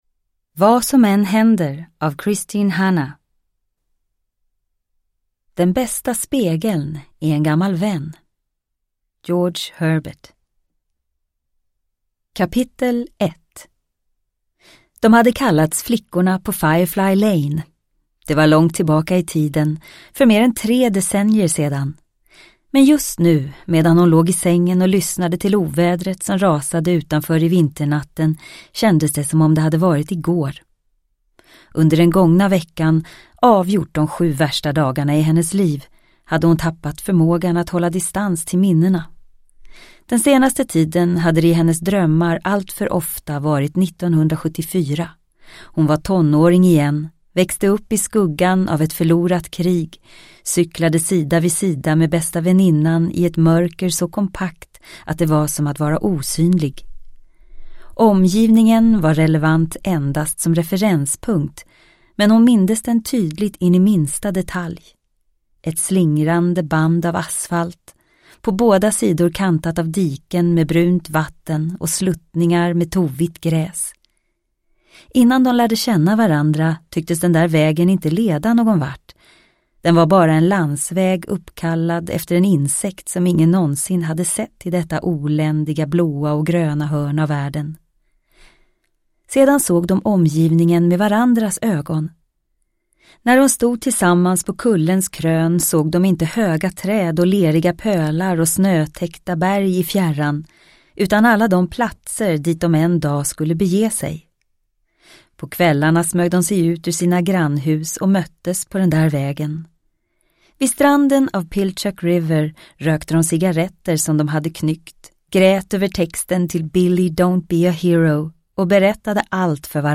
Vad som än händer – Ljudbok – Laddas ner